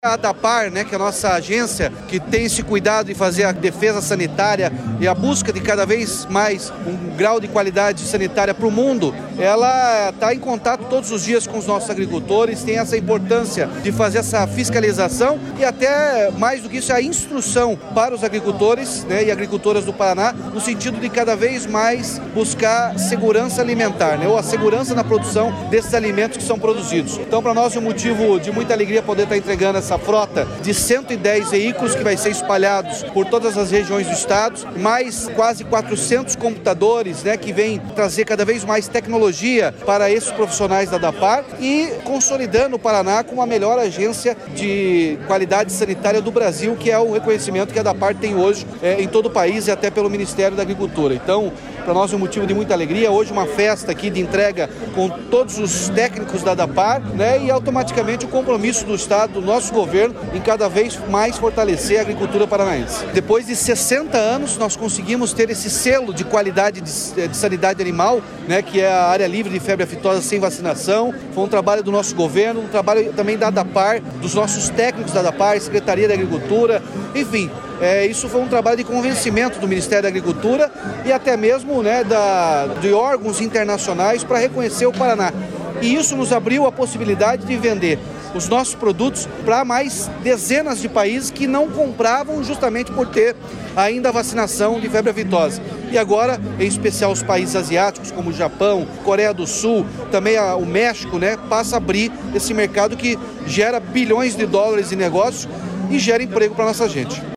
Sonora do governador Ratinho Junior sobre a entrega de veículos e computadores para a Adapar